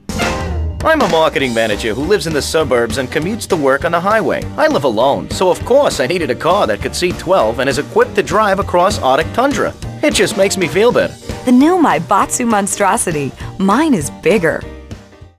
[[Category:Audio ads]]